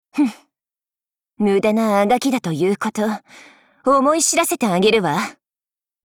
Cv-70301_battlewarcry_2.mp3 （MP3音频文件，总共长6.0秒，码率320 kbps，文件大小：236 KB）
贡献 ） 协议：Copyright，人物： 碧蓝航线:塔林语音 您不可以覆盖此文件。